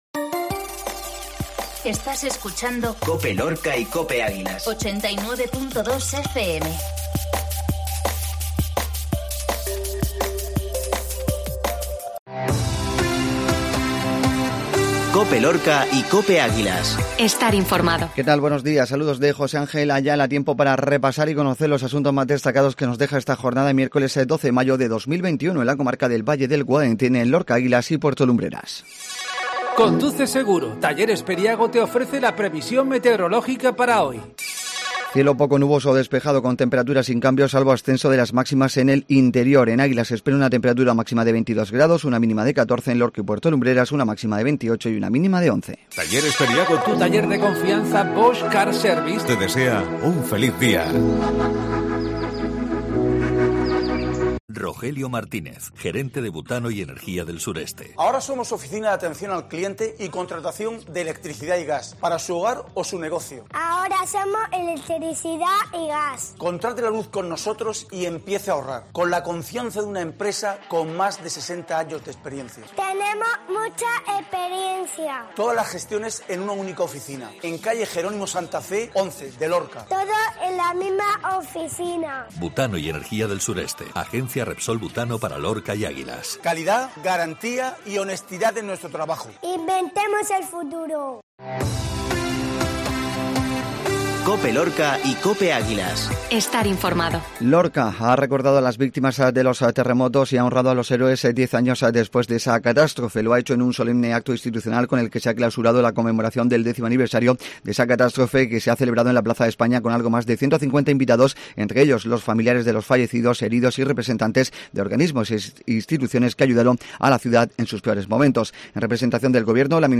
INFORMATIVO MATINAL MIÉRCOLES